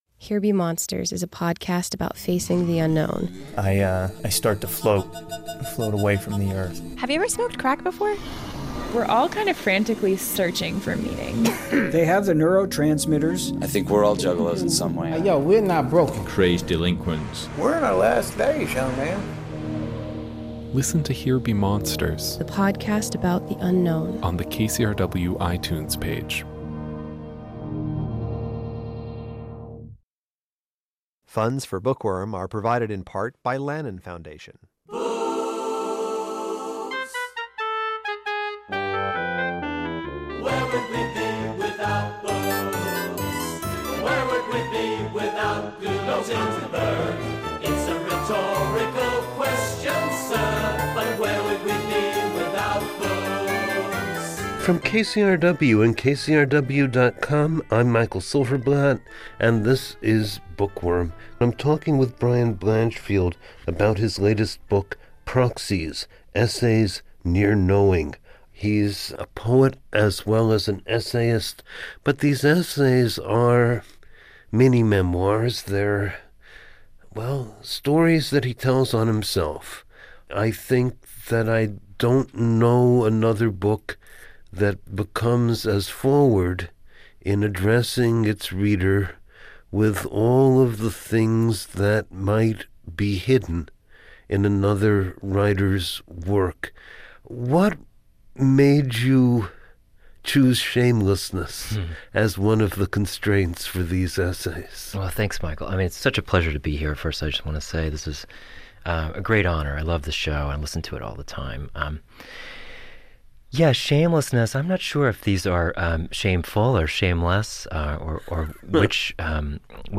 They reveal truths about being a queer poet who is sexually and romantically active in the post-AIDS era. He reads from his essay, “On Tumbleweed,” which reveals that he has tumbled through a series of relationships and academic jobs.